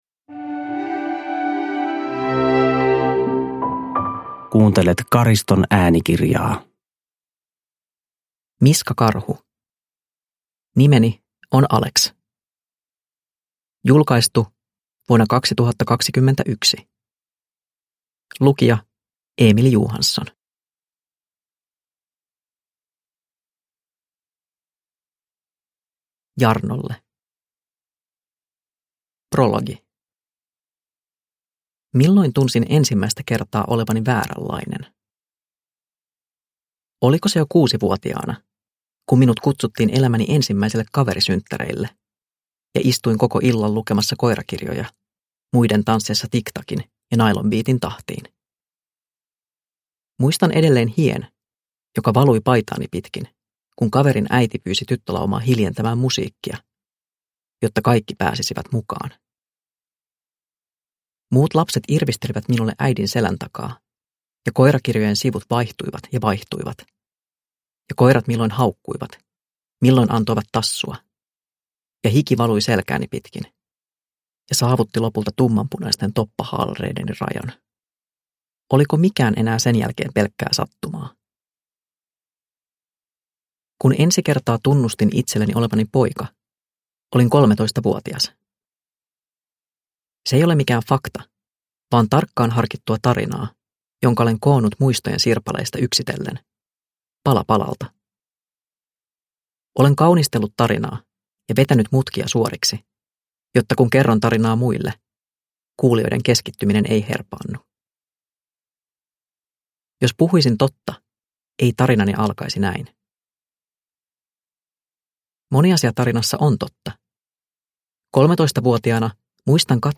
Nimeni on Alex – Ljudbok – Laddas ner